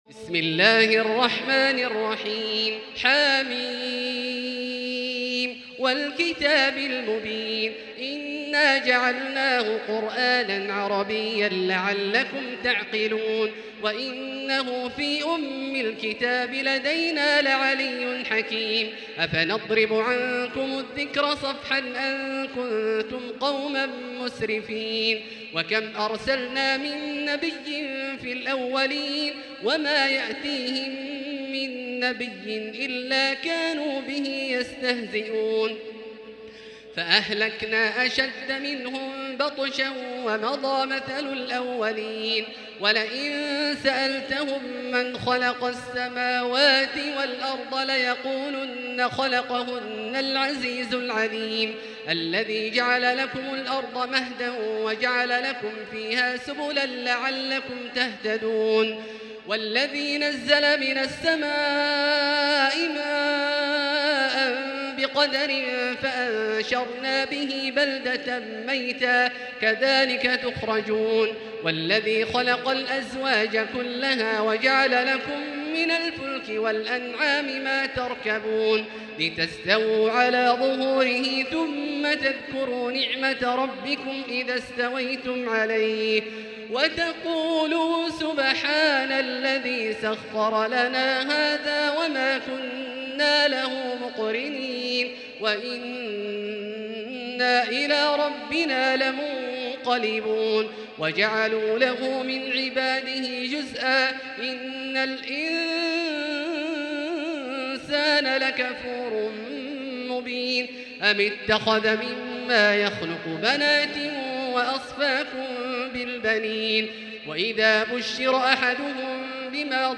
المكان: المسجد الحرام الشيخ: فضيلة الشيخ عبدالله الجهني فضيلة الشيخ عبدالله الجهني الزخرف